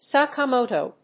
/sɑkɑˈmoto(米国英語), sɑ:kɑ:ˈməʊtəʊ(英国英語)/